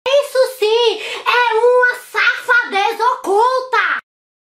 Mulher solta o meme Isso Sim É Uma Safadeza Oculta.